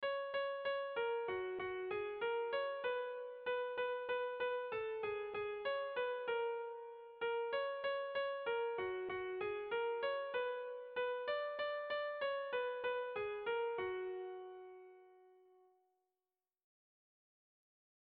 Sehaskakoa
Lauko handia (hg) / Bi puntuko handia (ip)
A1A2